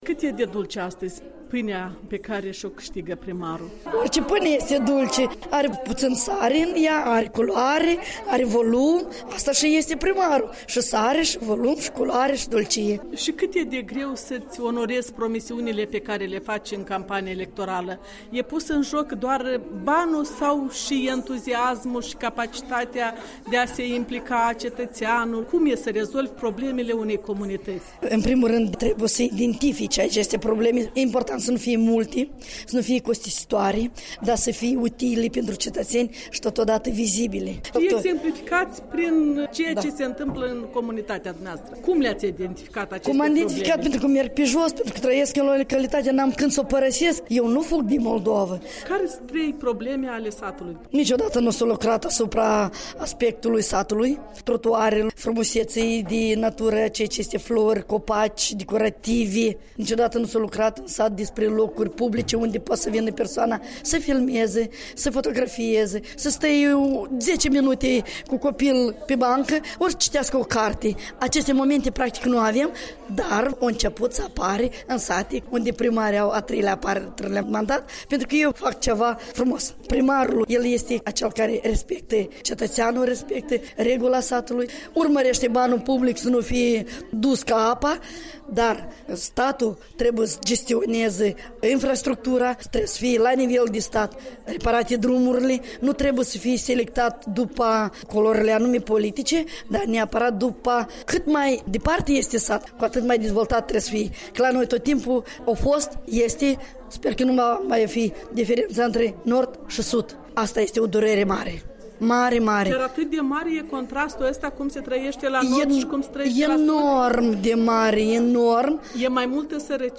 Despre raporturile între stat și puterea locală: un interviu cu un primar independent